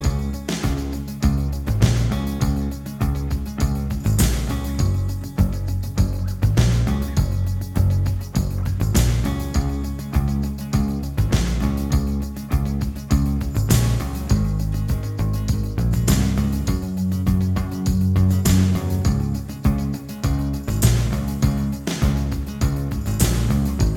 Minus Guitars Indie / Alternative 4:31 Buy £1.50